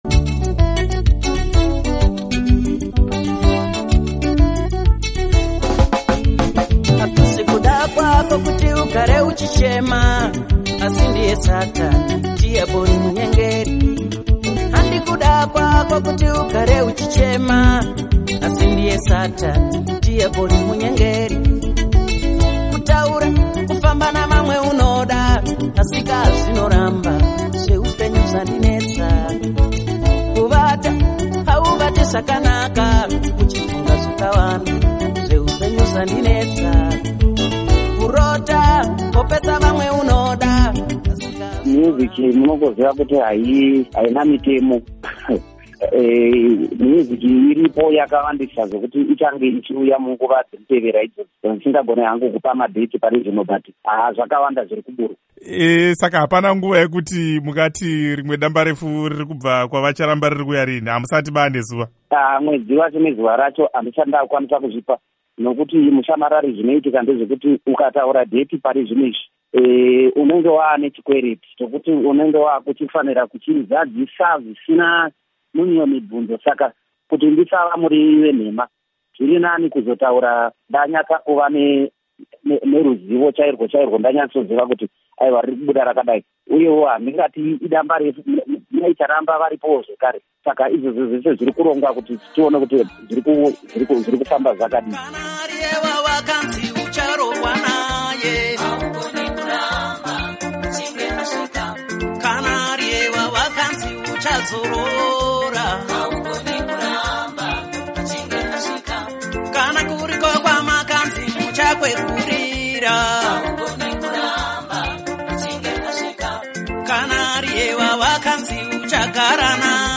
Hurukuro naBababa Charles Charamba